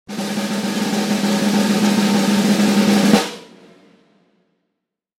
Drum Roll With Rimshot Sound Effect
Dramatic drum roll followed by a classic rimshot. Ideal for comedy punchlines, stage cues, video transitions, and entertainment projects.
Drum-roll-with-rimshot-sound-effect.mp3